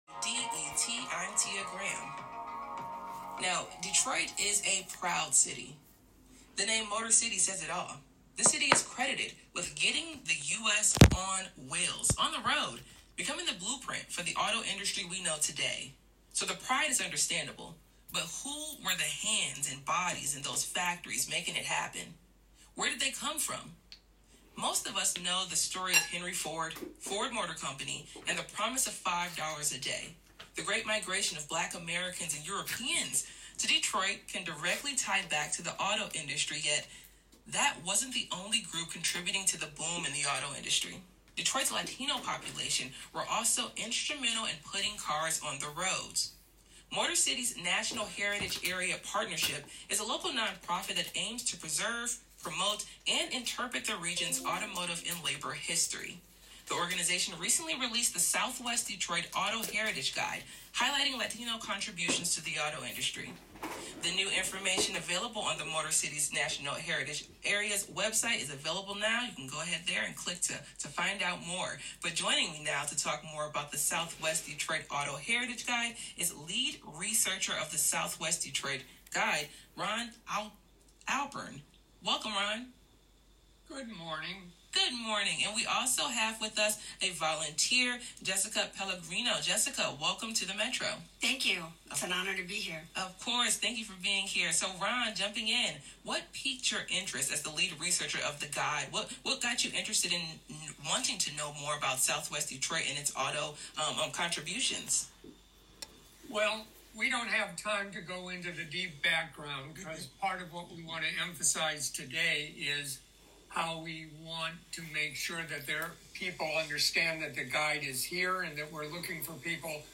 9-29-25_WDET_SW_Detroit_Interview.mp3